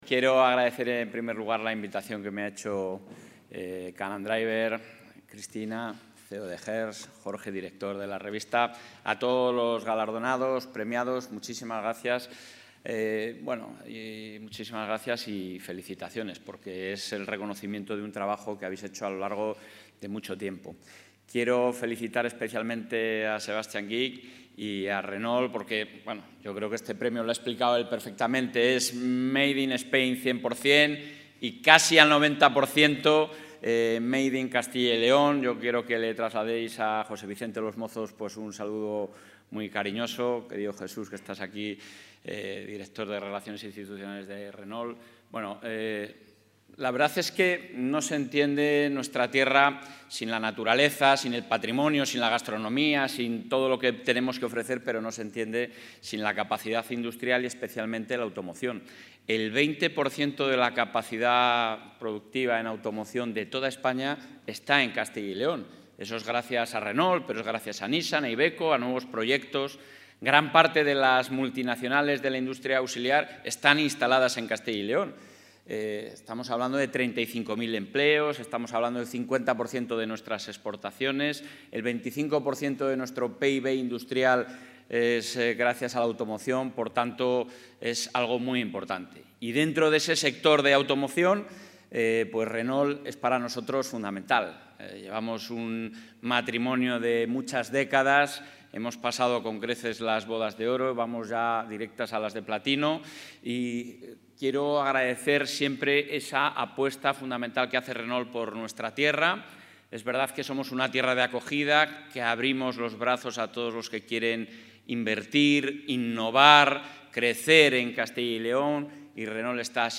Intervención del presidente de la Junta.
El presidente de la Junta de Castilla y León, Alfonso Fernández Mañueco, ha asistido en Madrid a los XXVIII Premios Car and Driver, otorgados por la revista del grupo Hearst Magazine, entre los que ha resultado galardonado el nuevo modelo Austral E-Tech full hybrid de Renault, fabricado en la planta de Palencia.